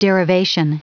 Prononciation du mot derivation en anglais (fichier audio)
Prononciation du mot : derivation